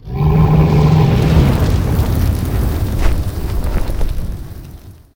CosmicRageSounds / ogg / general / combat / creatures / dragon / bellow.ogg
bellow.ogg